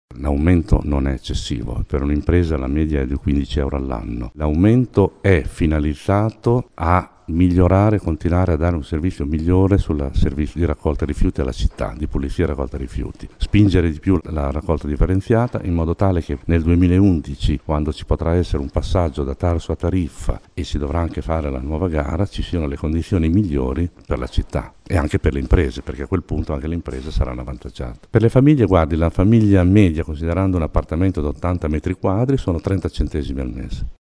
Ascolta l’assessore al bilancio Villiam Rossi sull’aumento della Tarsu